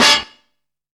JUS STAB.wav